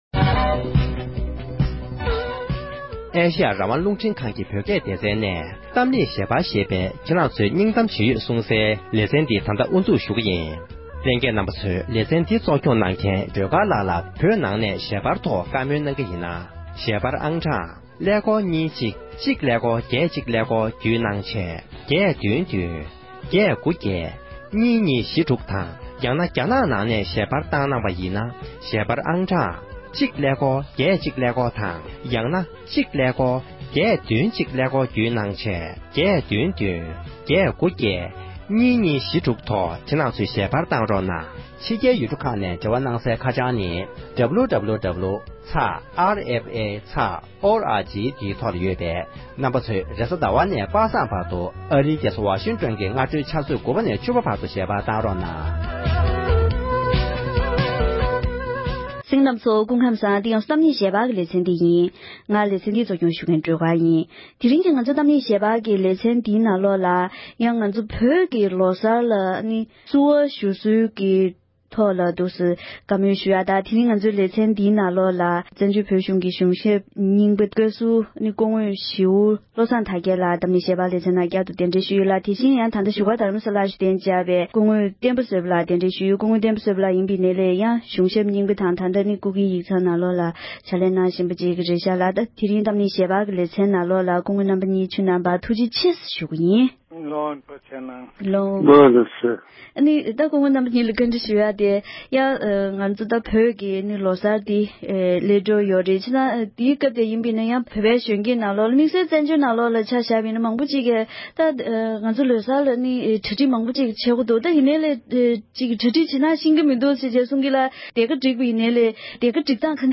བོད་ཀྱི་ལོ་གསར་བྱུང་སྟངས་དང་གྲ་སྒྲིག་བྱ་ཕྱོགས་སྐོར་བགྲོ་གླེང༌།